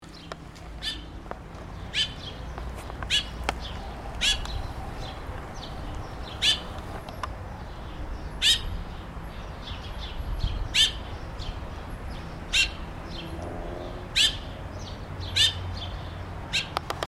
Zorzal Patagónico (Turdus falcklandii)
Nombre en inglés: Austral Thrush
Localidad o área protegida: Valle Inferior del Río Chubut (VIRCH)
Condición: Silvestre
Certeza: Observada, Vocalización Grabada